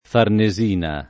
Farnesina [ farne @& na ]